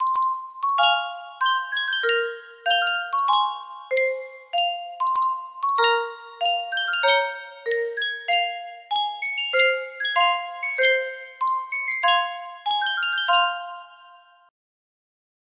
Sankyo Music Box - 刈干切唄 DAJ
Full range 60